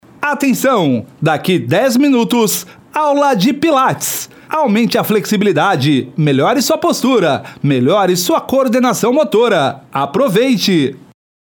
Aviso de aulas
Aula-de-Pilates.mp3